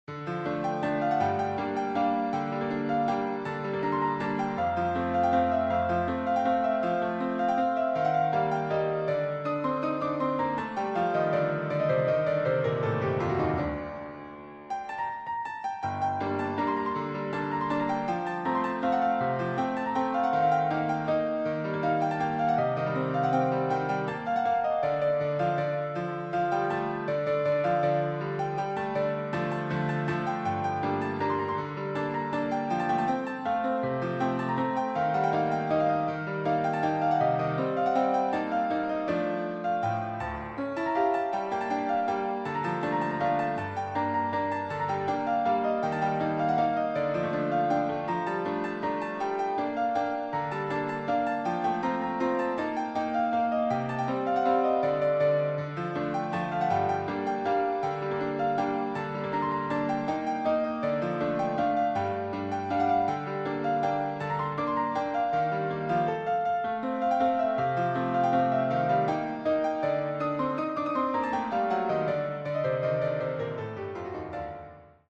Müəllif: Azərbaycan Xalq Mahnısı